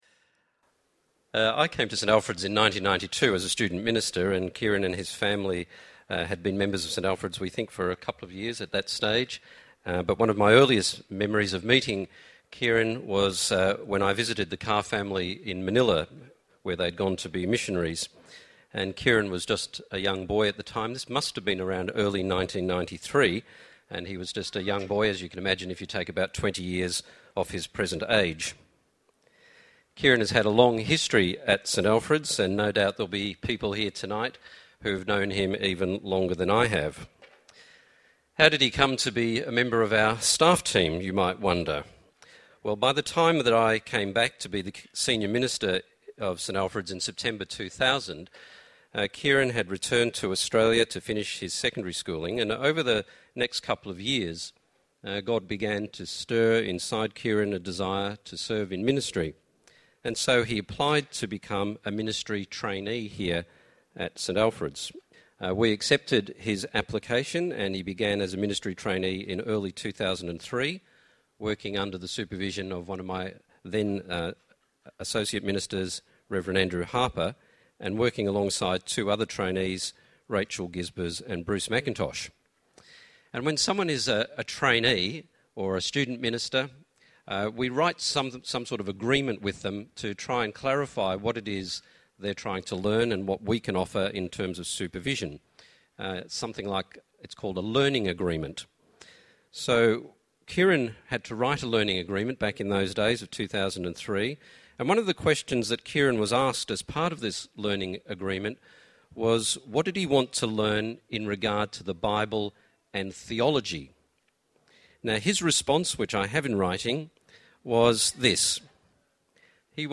Farewell Service
Current Sermon